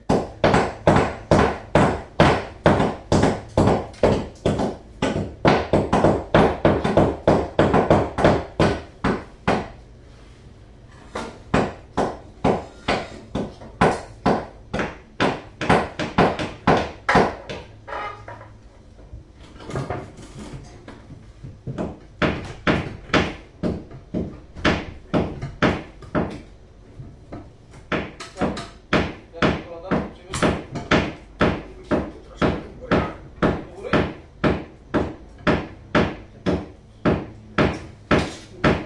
钻探 " 锤击 (1)
描述：所有这些都是用Zoom H2录制的。
钻孔声、敲击声、脚手架平台的声音（类似于索尼M10的录音）和有人说。
Tag: 锤击 电动工具 机械 嗡嗡声 噪音 钻探 建筑 机械 重击 工作 嗡嗡声 高层 CIT Y